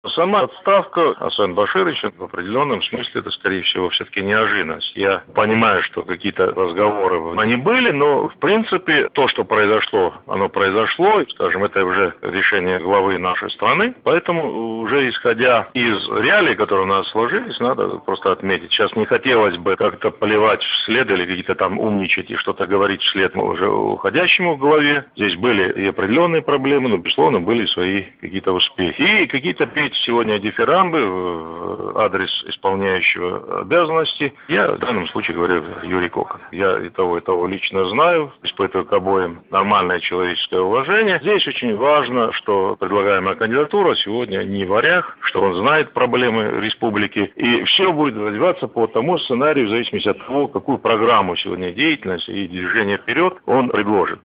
Передача радиостанции "Голос России"